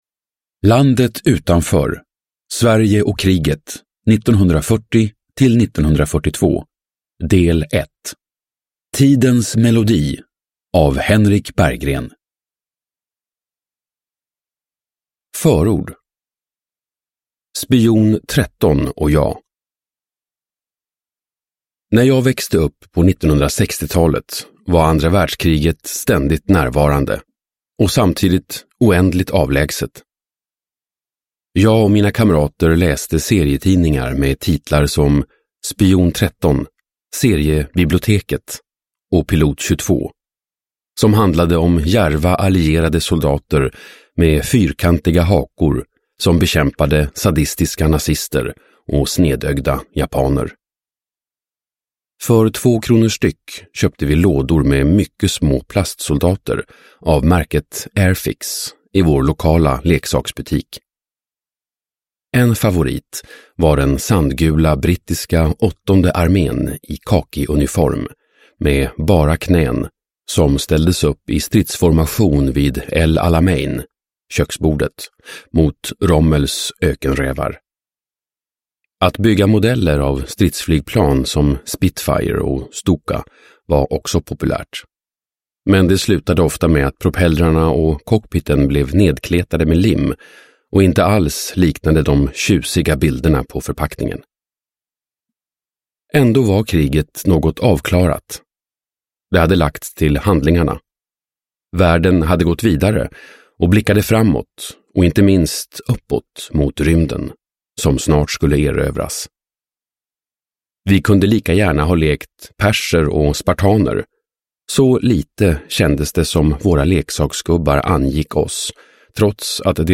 Landet utanför : Sverige och kriget 1940-1942. Del 2:1, Tidens melodi – Ljudbok – Laddas ner